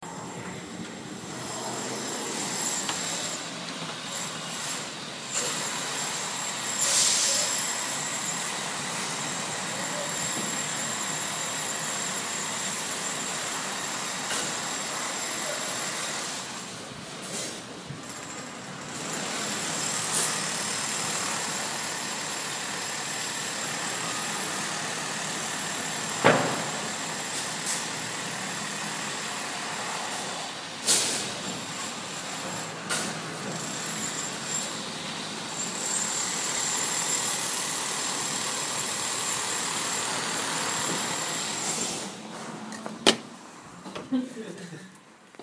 氛围 建筑工地外有一些交通
描述：一个用于刮土机的施工现场，在附近的公园路上记录在Zoom H5上设置为XY。 一些交通和一般建筑的声音，从扩音器上的网站发布一些公告。
Tag: 建设者 起重机 建设 工人 工作 工人 锤子 建设 建筑